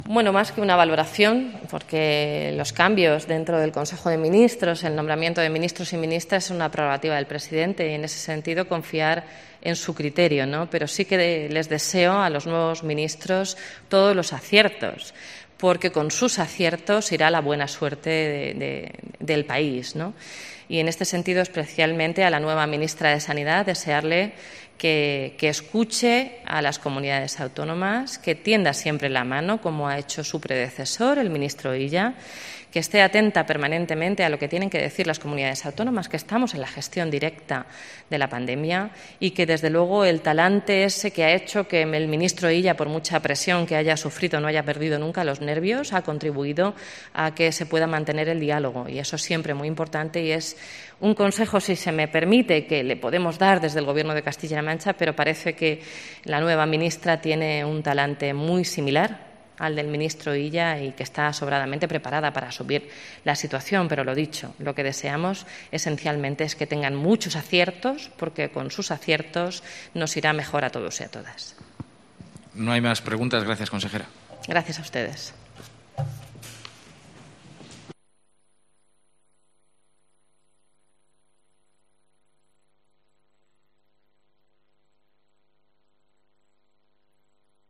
Declaraciones de la portavoz de C-LM sobre Darias e Iceta